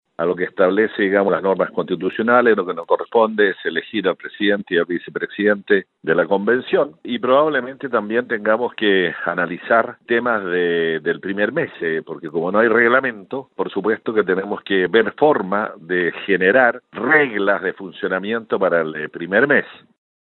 El constituyente de Vamos por Chile, Harry Jürgensen, señaló que a falta de un reglamento oficial, deberán proponer reglas provisorias de funcionamiento.